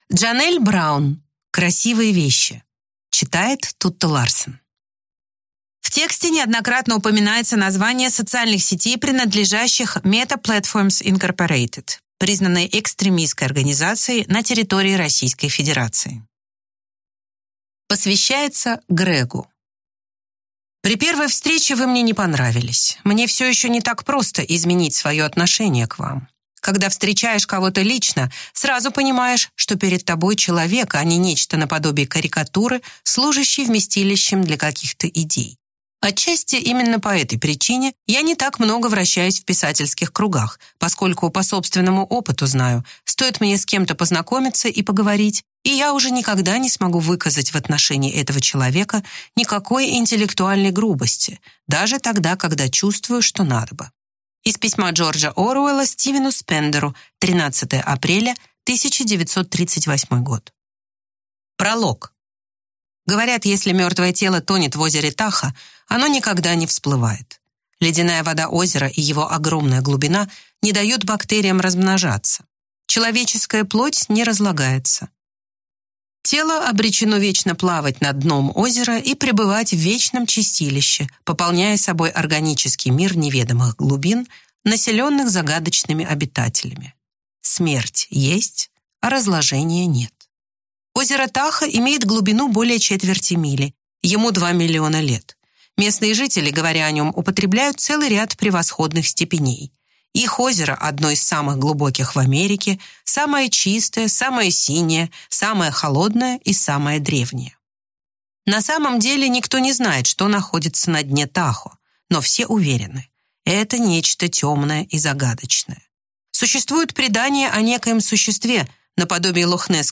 Аудиокнига Красивые вещи | Библиотека аудиокниг